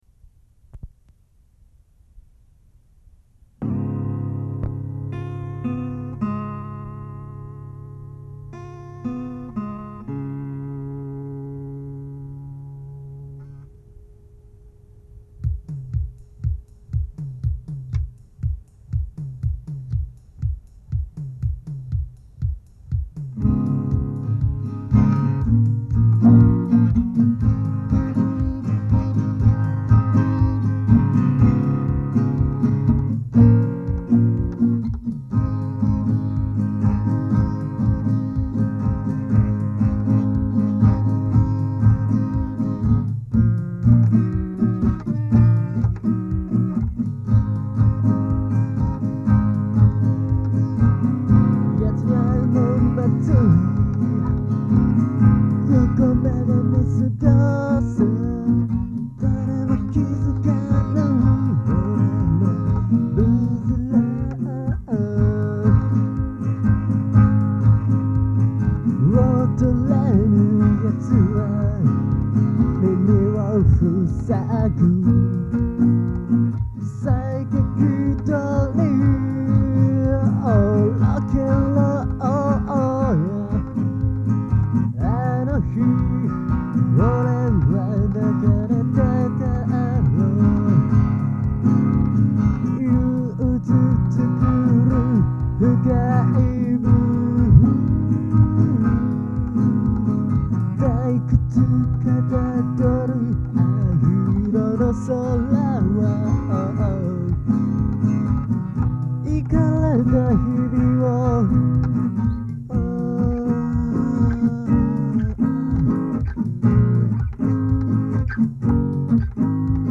曲の説明 この曲はフォークとロックを融合したものです。